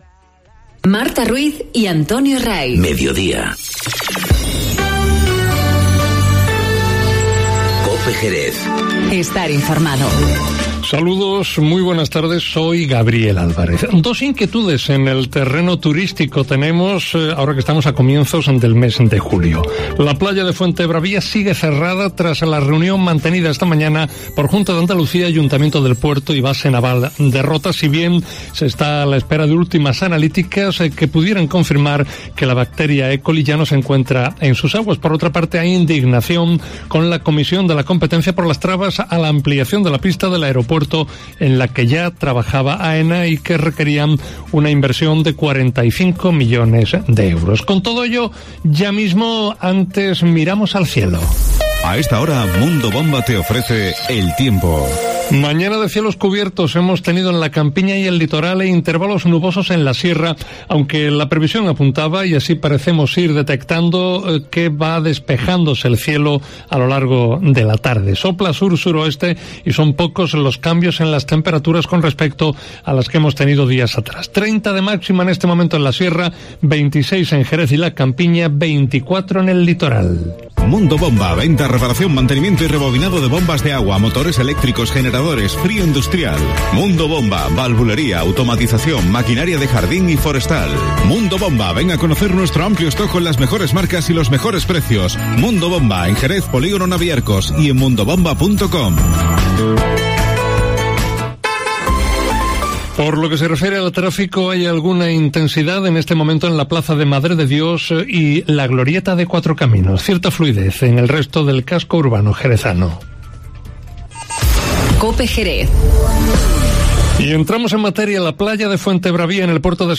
Informativo Mediodía COPE en Jerez 04-07-19